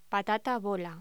Locución: Patata bola
voz